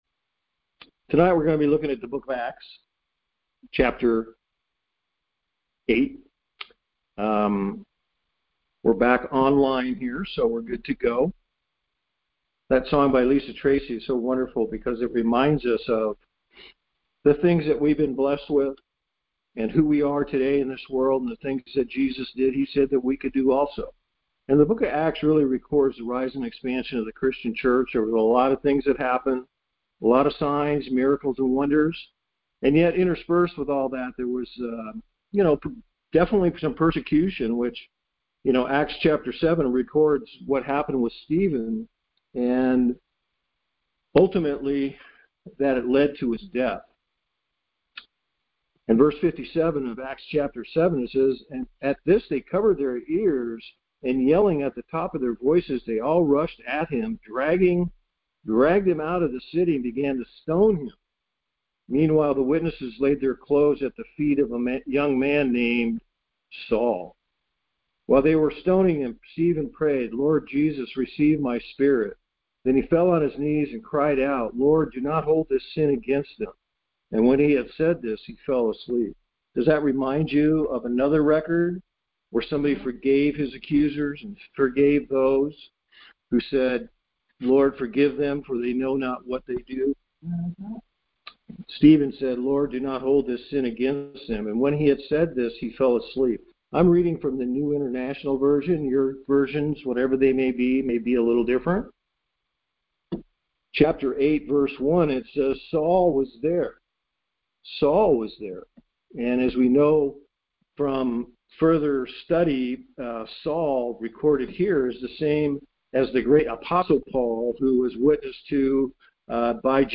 Conference Call Fellowship